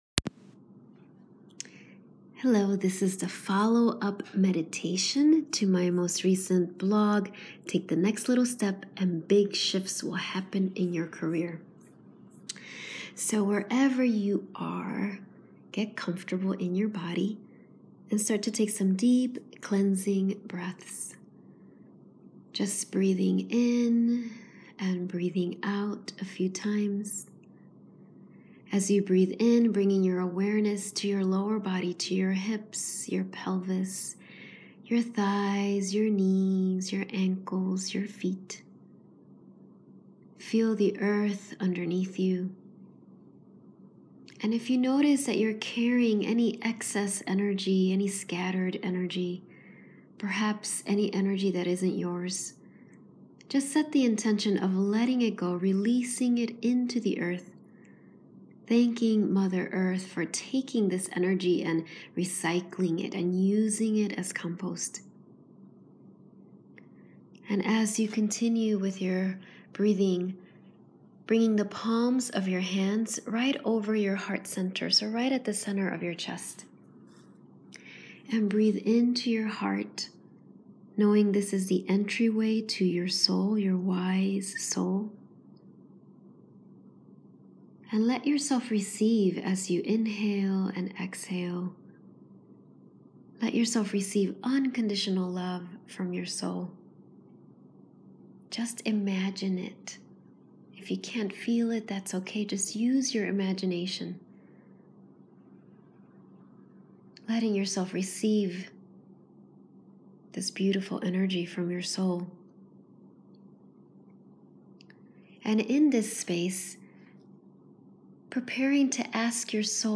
Take-next-step-meditatioin.m4a